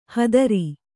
♪ hadari